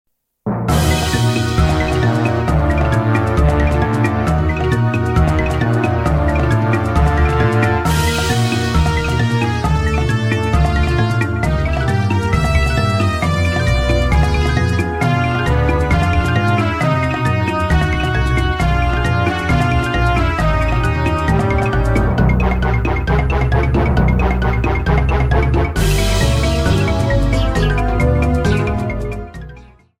30 seconds and fadeout You cannot overwrite this file.